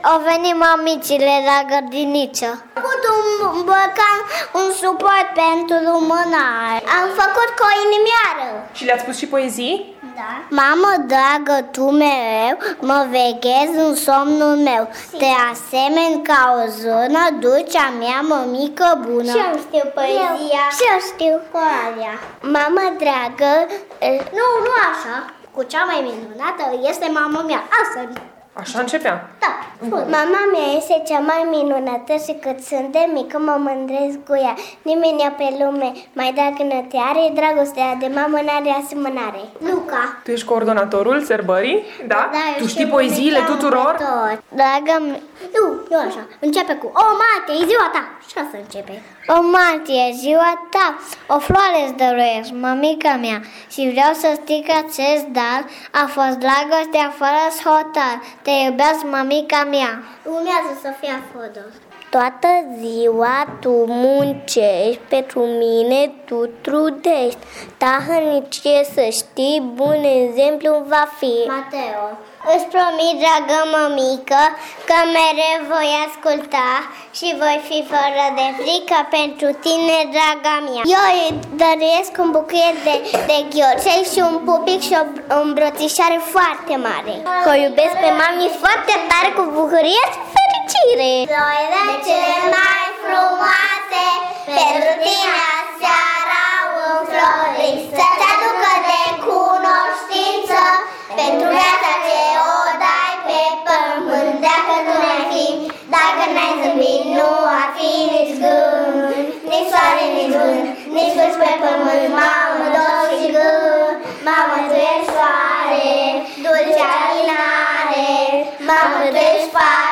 Preșcolarii din grupele mari Steluțele și Fluturașii de la grădinița nr. 10 din Tg-Mureș le-au invitat pe mămicile lor la serbarea organizată de 8 martie și le-au surprins cu poezii și cântece alese.